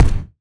Drum Sounds & Kits
Gleichzeitig haben die Klänge einen lässigen, oft geradezu frechen Touch.
Kick-Conundrums-11-2.mp3